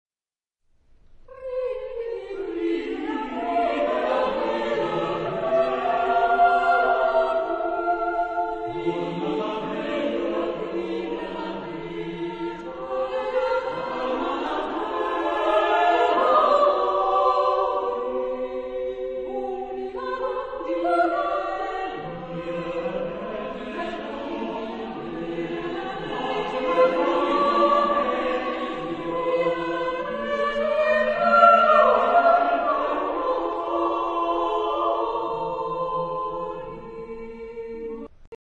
Género/Estilo/Forma: Madrigal ; Profano
Tipo de formación coral: SSATB  (5 voces Coro mixto )
Tonalidad : mixolidio
por Neuer Kammerchor Potsdam
Ref. discográfica: 7. Deutscher Chorwettbewerb 2006 Kiel